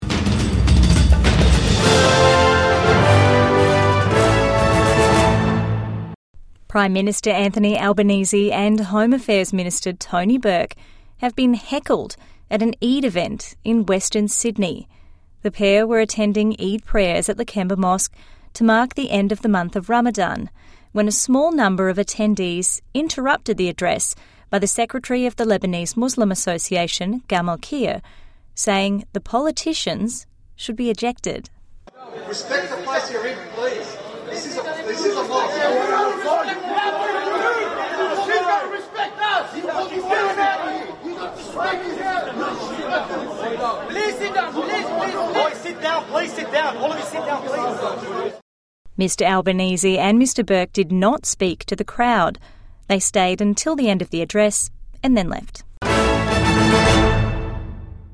Albanese, Bourke heckled at Eid service at mosque